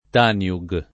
vai all'elenco alfabetico delle voci ingrandisci il carattere 100% rimpicciolisci il carattere stampa invia tramite posta elettronica codividi su Facebook Tanjug [ t # n L u g ] n. pr. f. — sigla di Telegrafska Agencija Nove Jugoslavije (serbocr.)